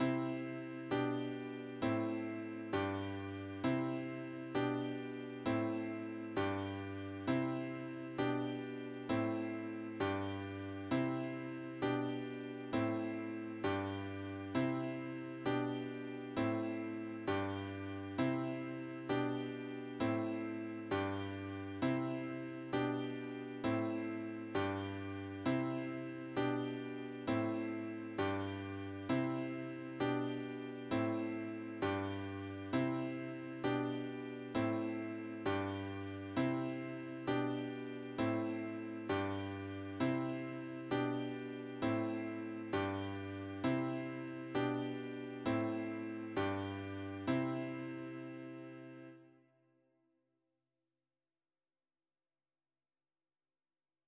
Accompaniment mp3